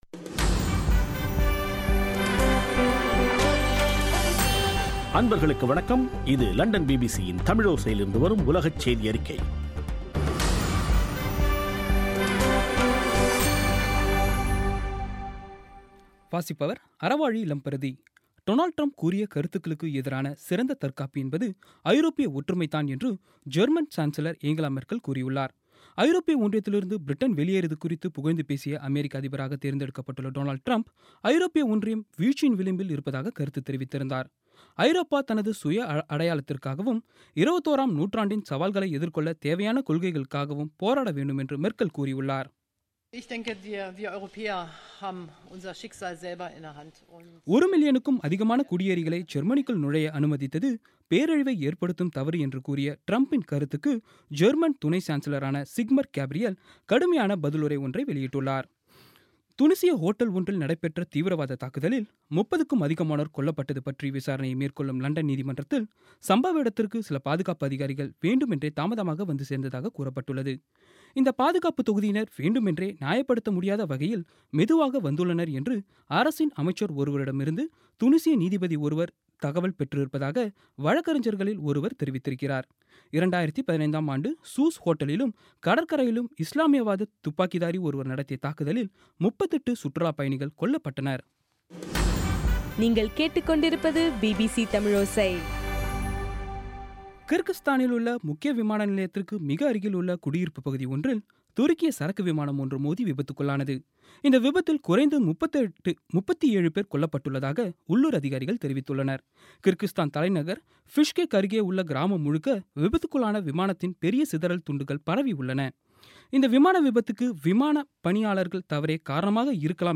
பிபிசி தமிழோசை செய்தியறிக்கை (16/01/2017)